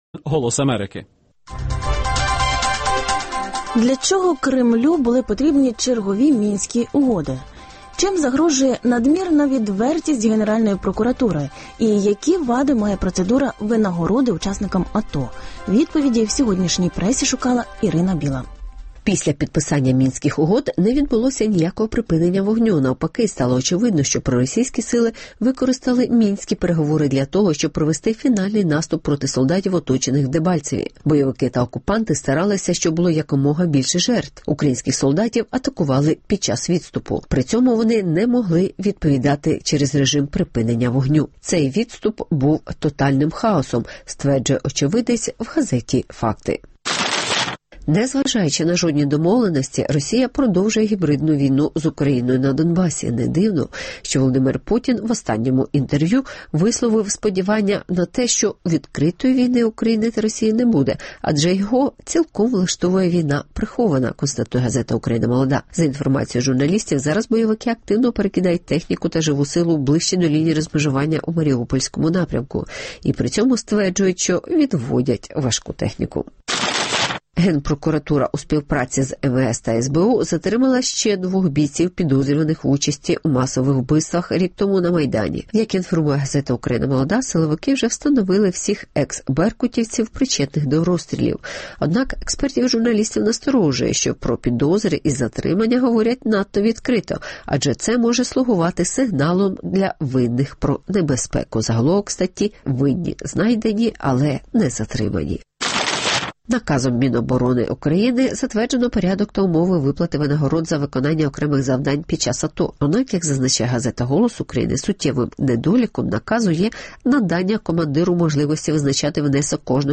Винагорода учасникам АТО: корупційна схема чи додаткова мотивація? (Огляд преси)